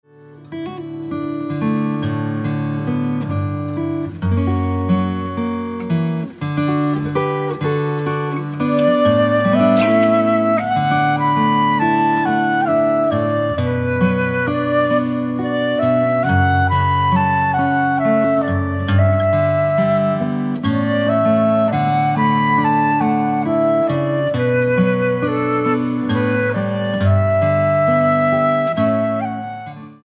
Traditional hymn instrumentals for guitar, violin and flute